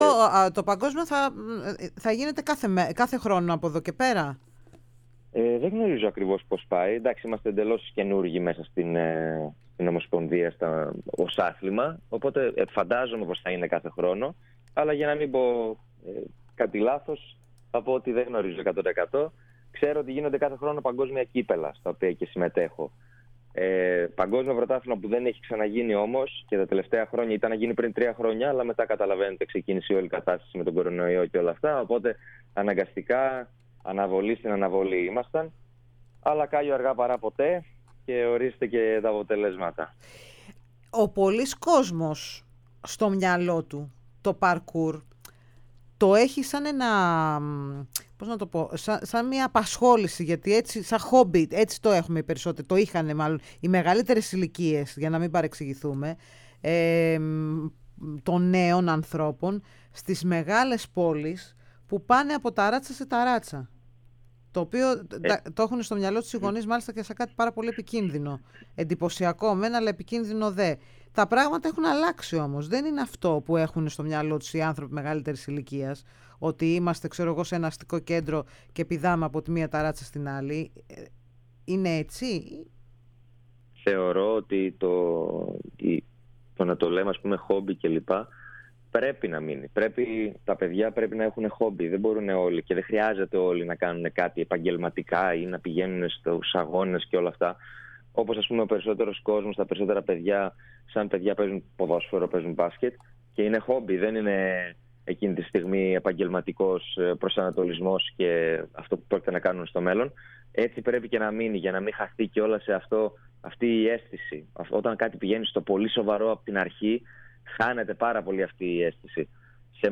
Ο Έλληνας αθλητής, μίλησε στην ΕΡΑ Σπορ και την εκπομπή “η Λαίδη και ο Αλήτης”